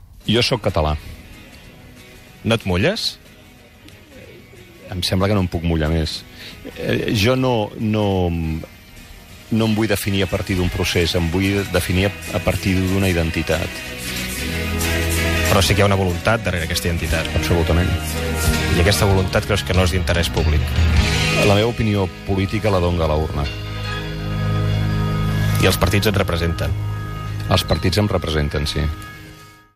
Fragment del primer programa, fet amb el periodista Antoni Bassas
Entreteniment
FM